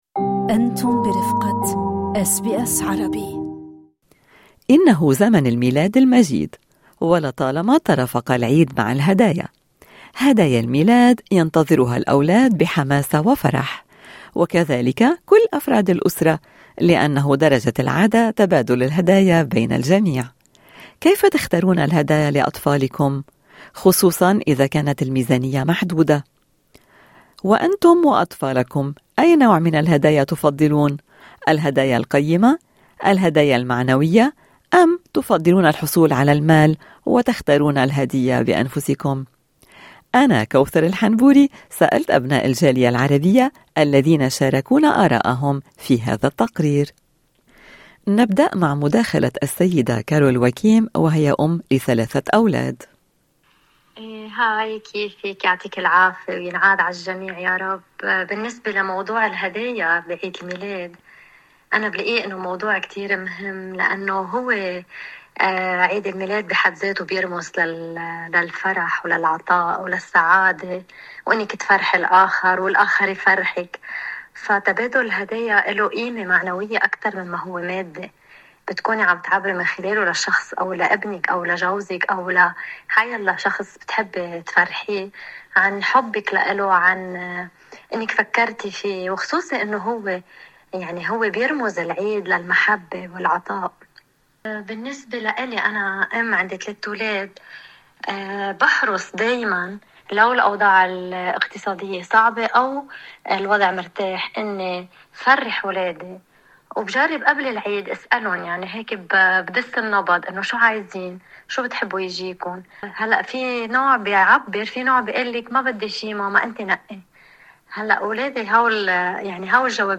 استطلعنا أبناء الجالية العربية الذين شاركونا أراءهم في تقرير صوتي